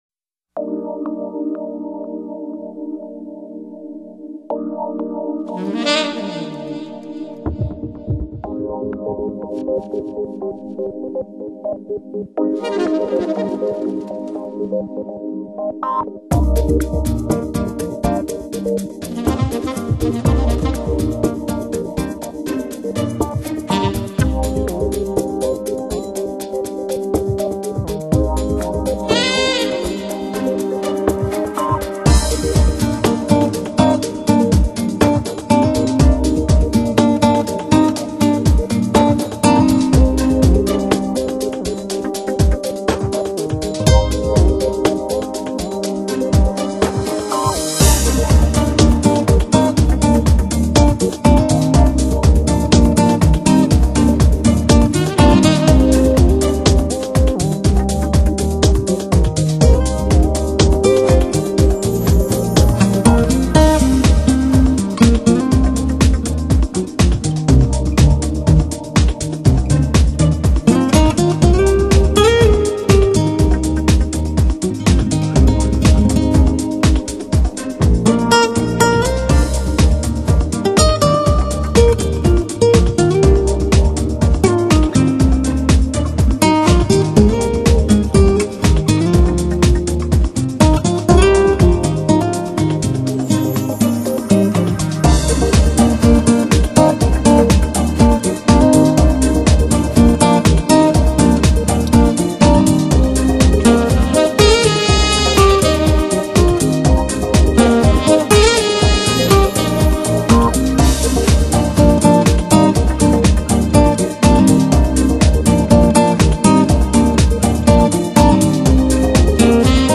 经典爵士乐
jazz guitar